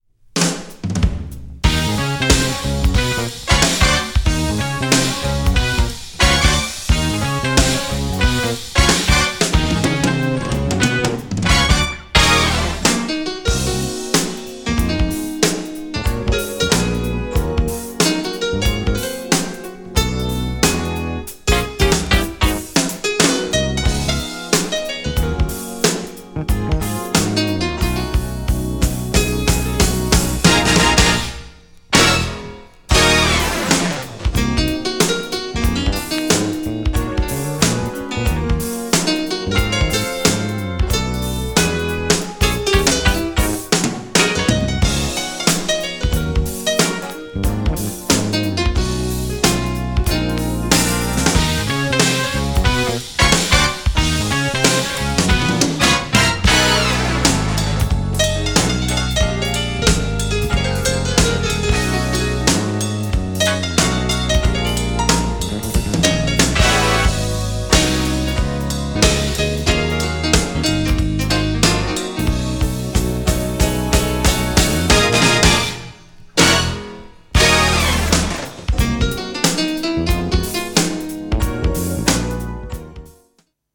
B面はもっとモダンなJAZZ INSTでこちらもド渋!!
GENRE Dance Classic
BPM 91〜95BPM
# INSTRUMENTAL # JAZZY